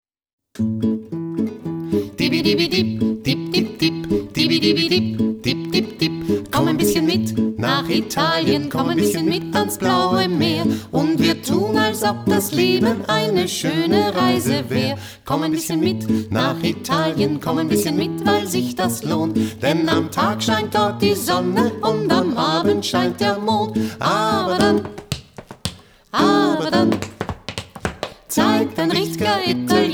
geige, stimme
gitarre, stimme
trompete
perkussion, schlagzeug